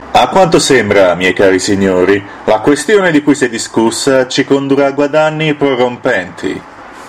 Versatile voice, ready to serve, proper, honest, dutyful, no time-waster.
Sprechprobe: Industrie (Muttersprache):